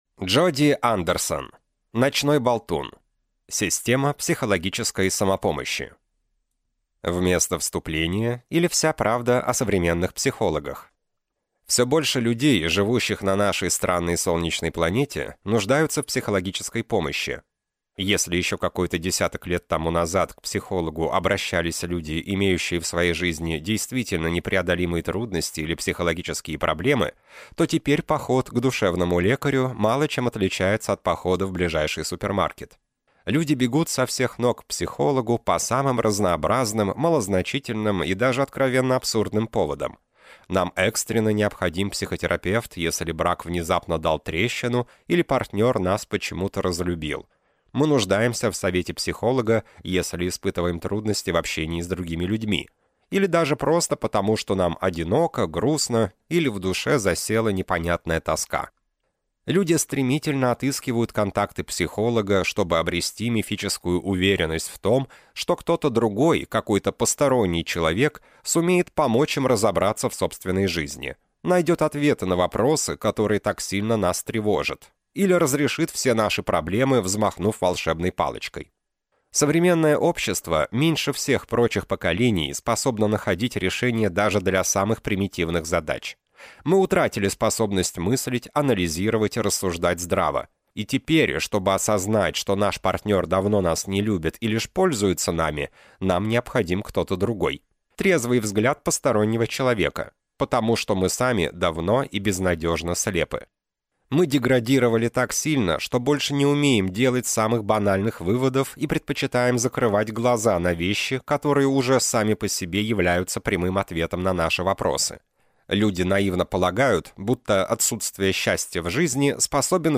Аудиокнига Ночной болтун. Система психологической самопомощи | Библиотека аудиокниг